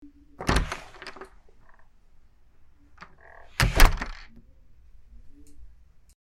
Download Doors sound effect for free.
Doors